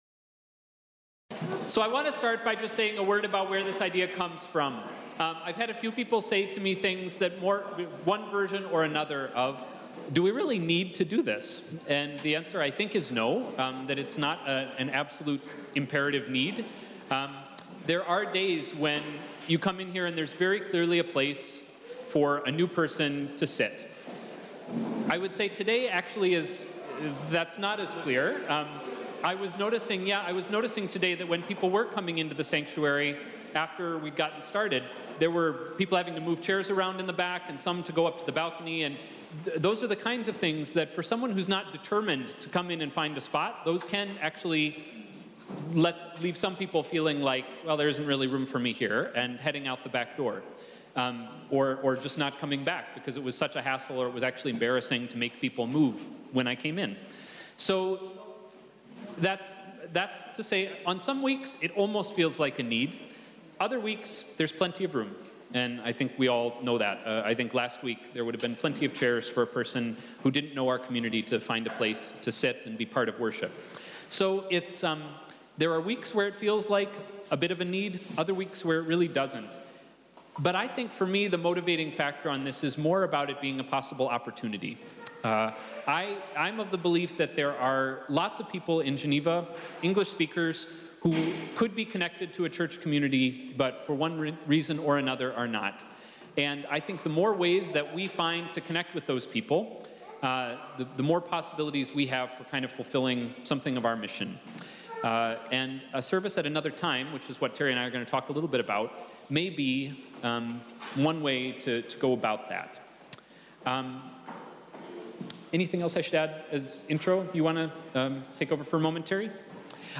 Sunday 11th March 2018 – after worship FORUM on the possibility of a second worship service (Sunday evening for example).